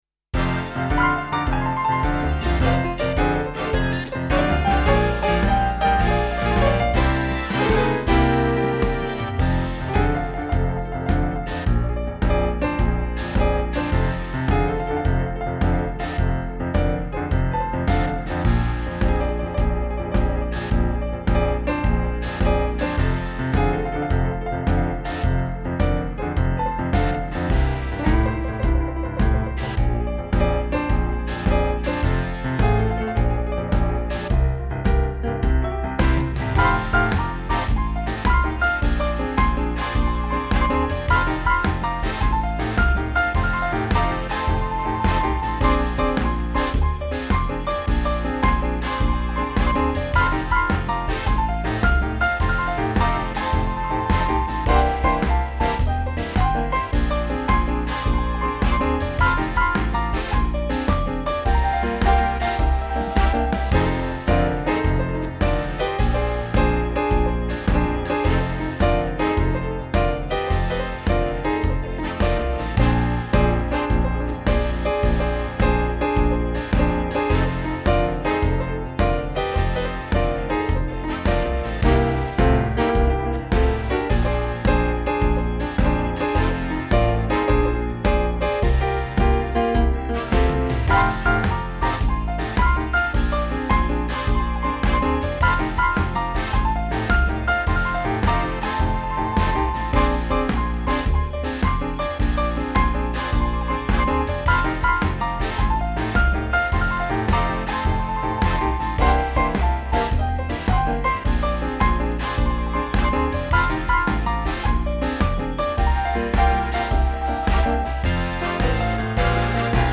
Home > Music > Blues > Bright > Laid Back > Elegant